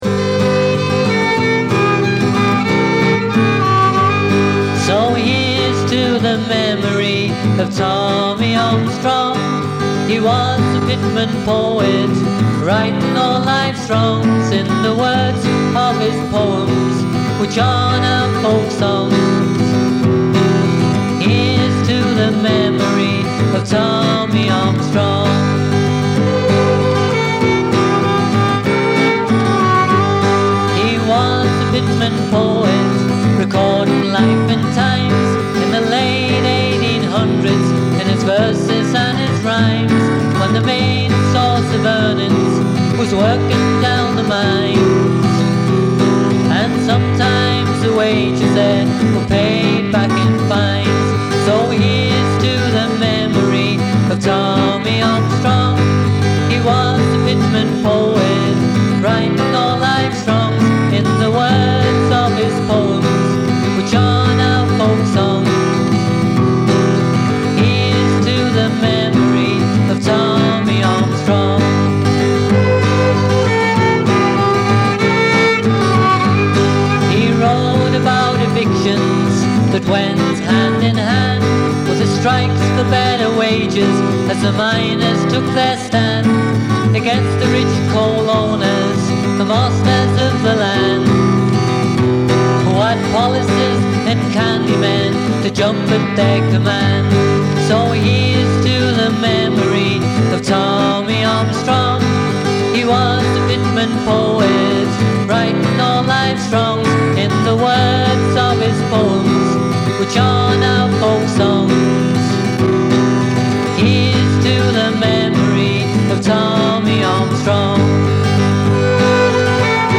violin
flute